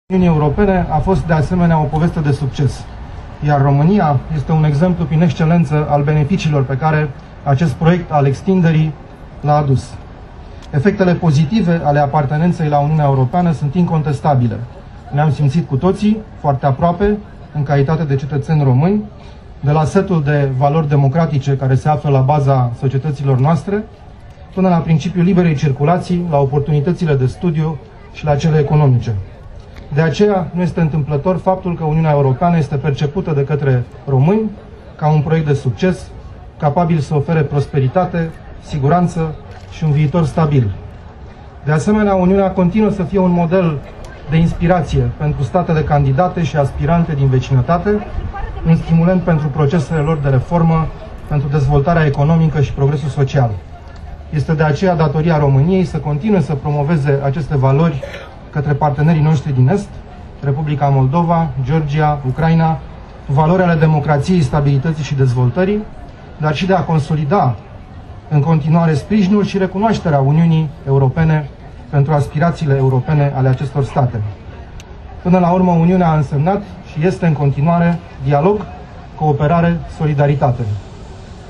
Primarul General Sorin Oprescu a sărbătorit Ziua Europei, împreună cu ministrul afacerilor externe Bogdan Aurescu, cu șefa Reprezentanței Comisiei Europene la București Angela Filote și cu ambasadori ai statelor membre UE la București, in Piața Statelor Uniunii Europene.
Ministrul afacerilor externe al Romaniei Bogdan Auirescu:
Aurescu-discurs.mp3